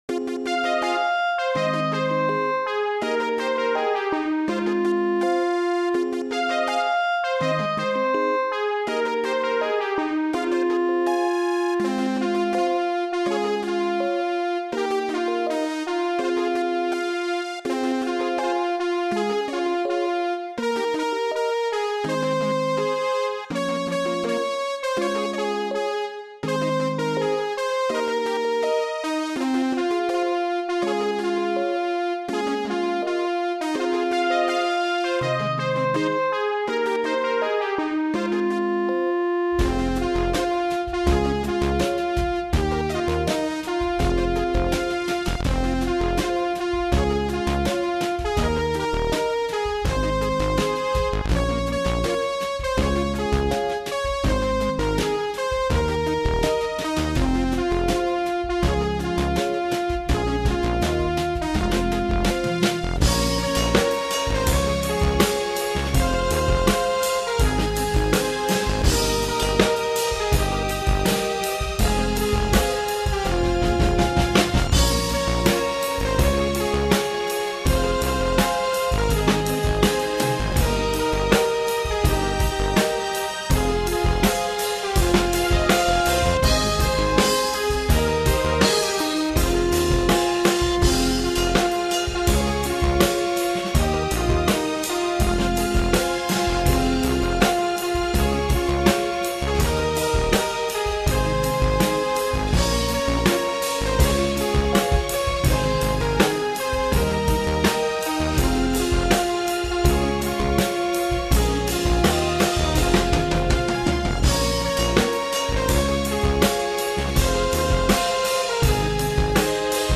My backing is a bit electronic.